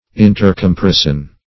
Search Result for " intercomparison" : The Collaborative International Dictionary of English v.0.48: Intercomparison \In`ter*com*par"i*son\, n. Mutual comparison of corresponding parts.